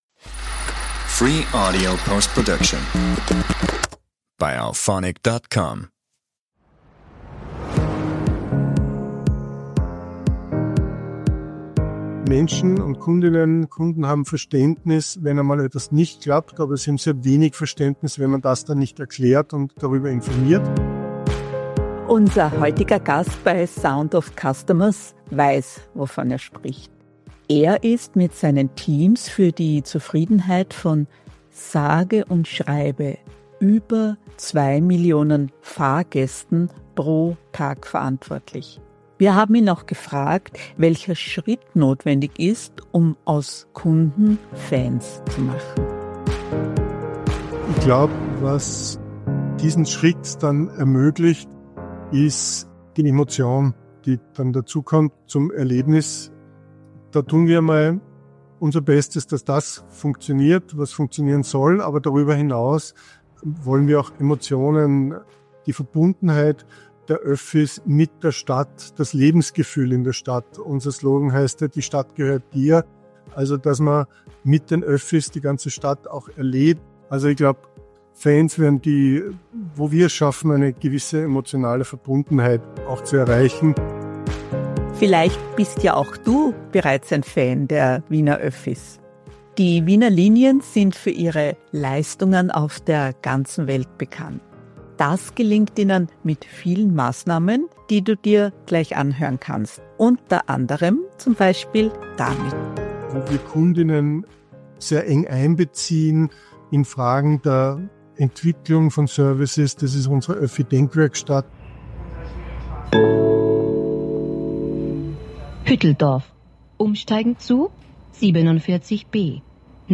Kundenzentrierte Leader im Fishbowl 3 (Live-Mitschnitt): Zwischen Employee- und Customer-Experience – Sound of Customers – Podcast